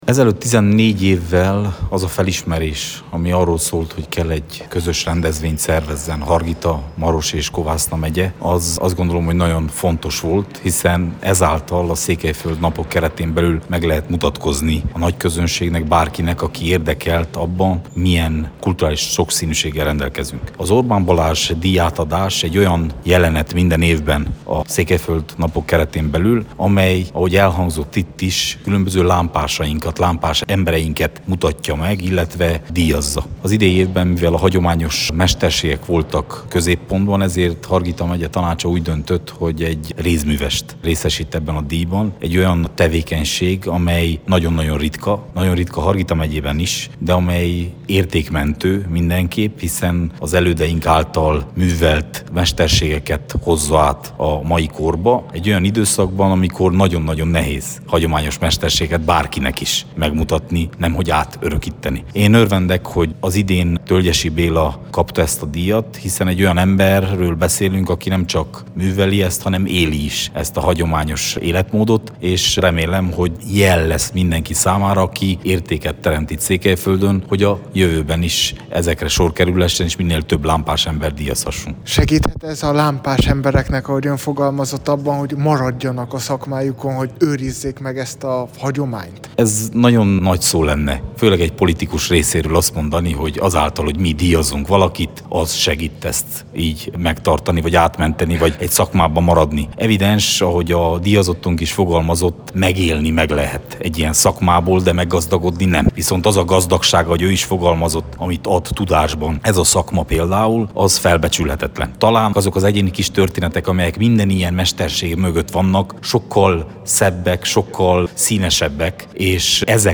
Az ünnepélyes díjátadóra október 1-én került sor Marosvásárhelyen, a Kultúrpalotában.
Az elődjeink szakmájának tovább örökítése egy nemes feladat, amelynek megvan a maga szépsége. Bár megélni meglehet belőlük, meggazdagodni anyagi értelemben nem, viszont a megélt történetek által a mesteremberek egy olyan vagyonra tesznek szert, amely kevés személynek adatik meg, fogalmazott Barti Tihamér, Hargita Megye Tanácsának alelnöke.